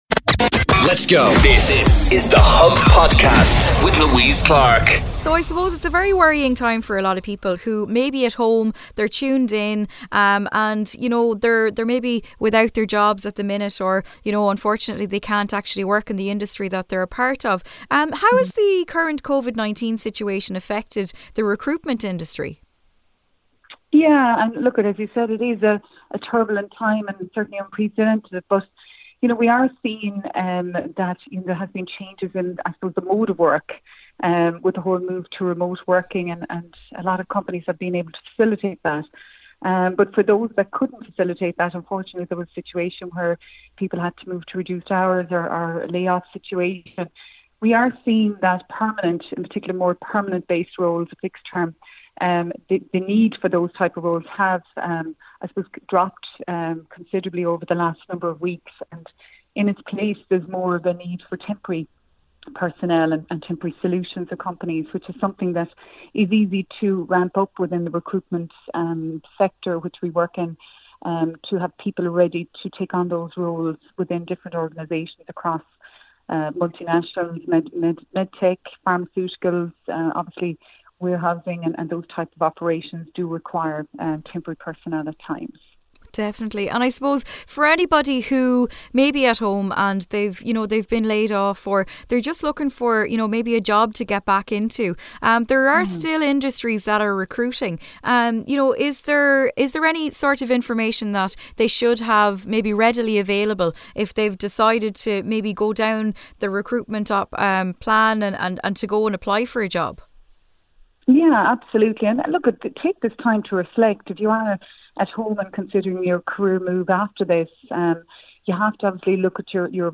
Ava Max Interview on iRadio - 23.07.2020